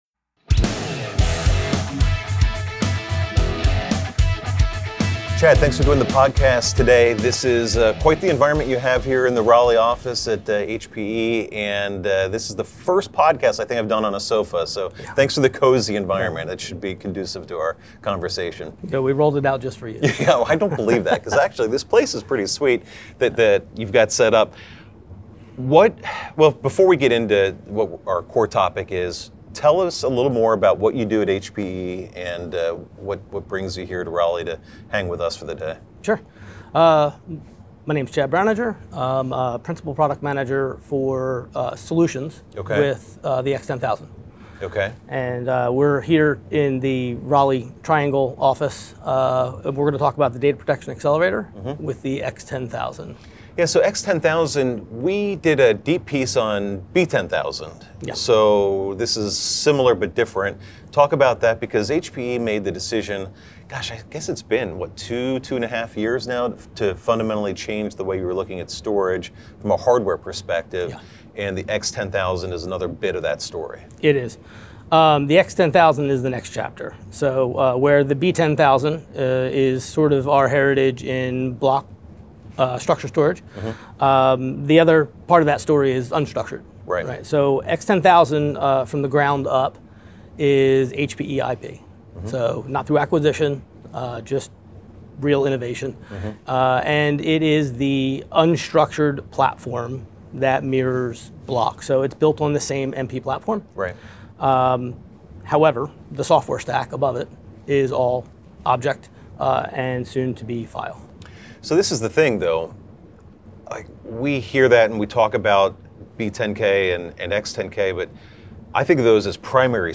This is a casual conversation despite the technical topic.